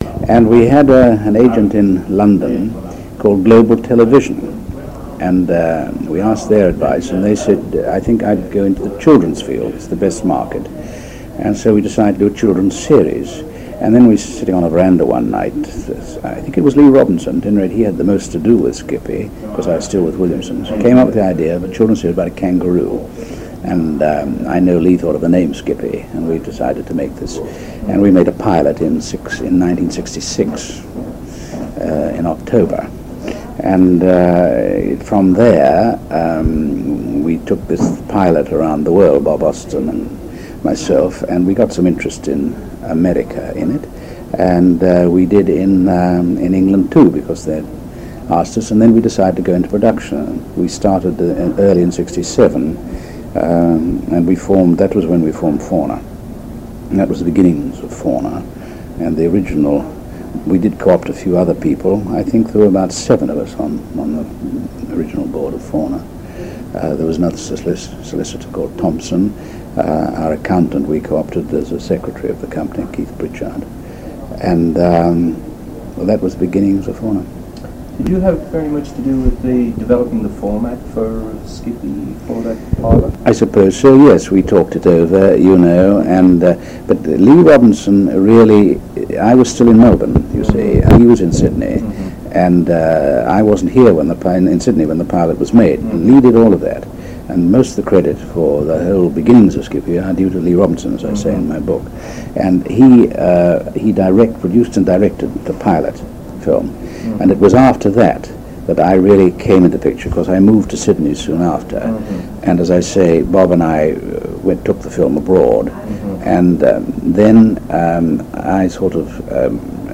John McCallum: oral history
82624-john-mccallum-oral-history